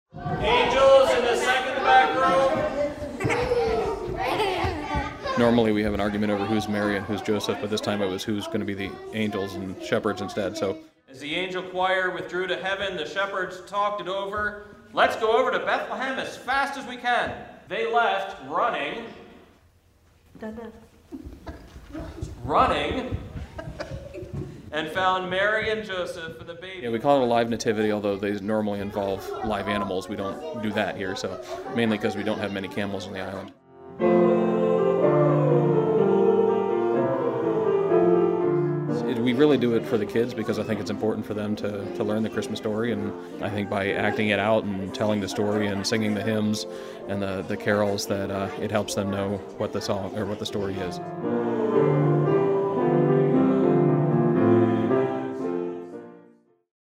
It was the annual living nativity -- part holiday pageant, part Christmas service.